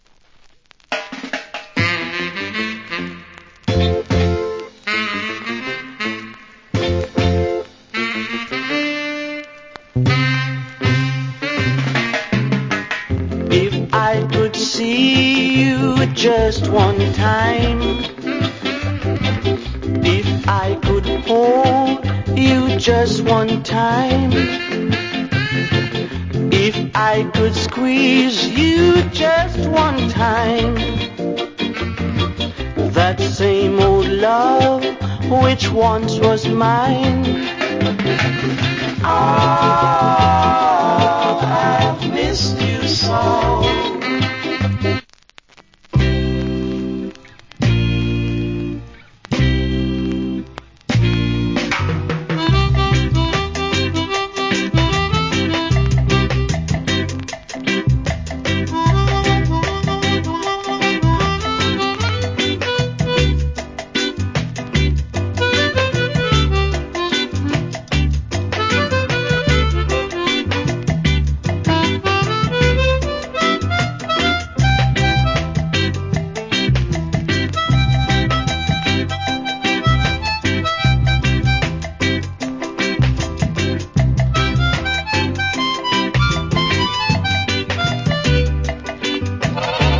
Cool Early Reggae Vocal.